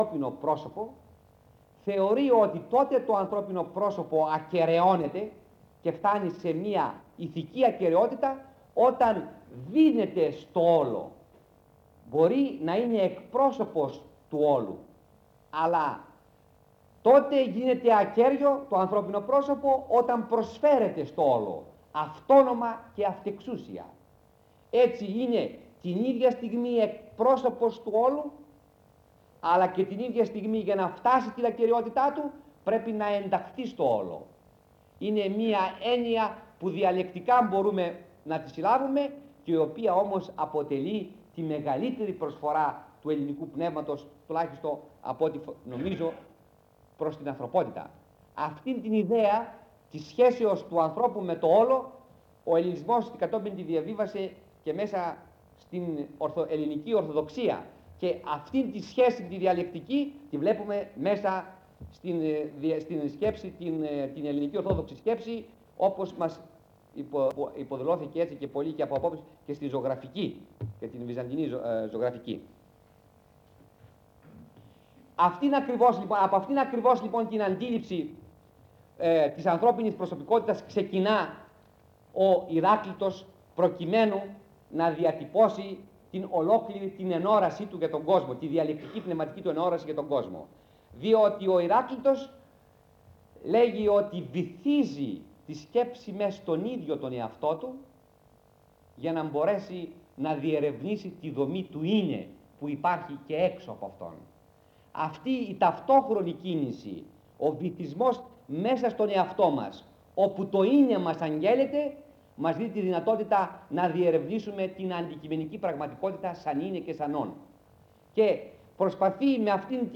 Λέξεις-Κλειδιά: κύκλος μαθημάτων; ηράκλειτος